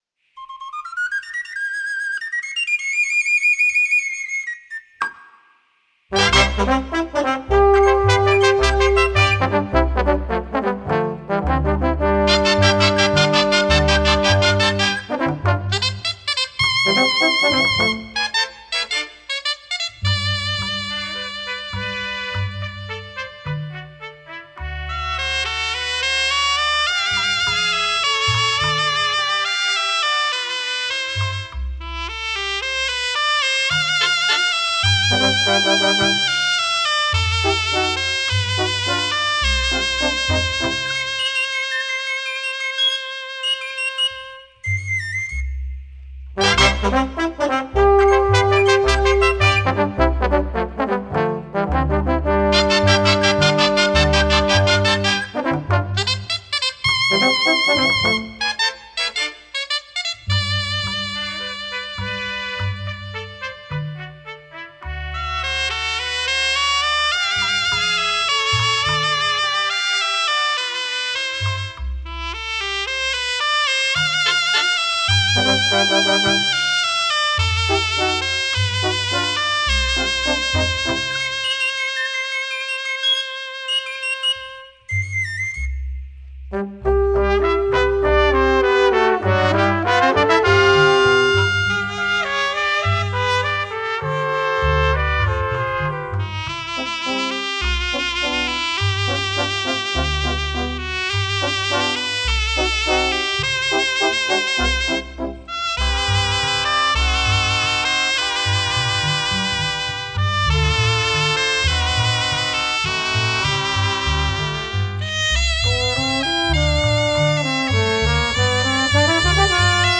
sardana
Festa de Sant Vicenç a Cabanes